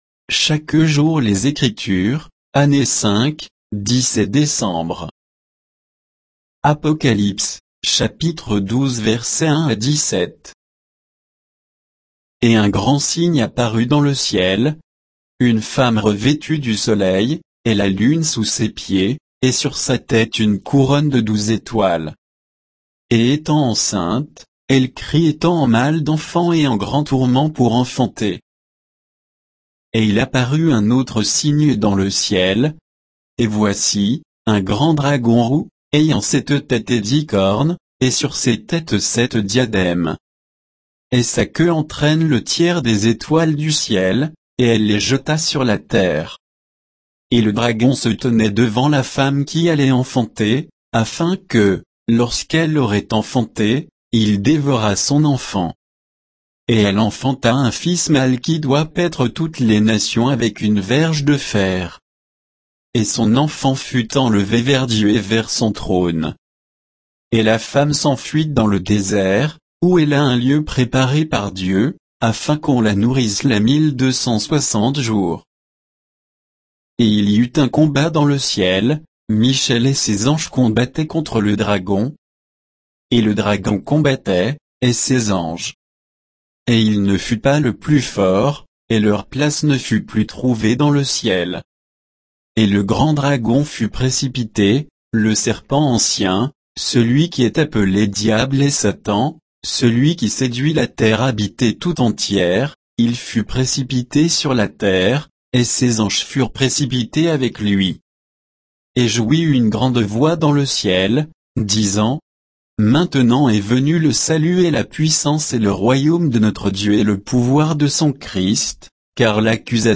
Méditation quoditienne de Chaque jour les Écritures sur Apocalypse 12